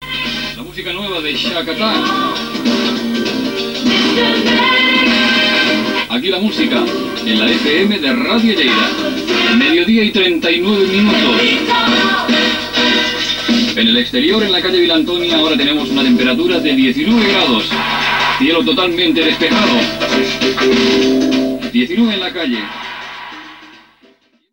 Tema musical, identificaciò, hora i temperatura
Musical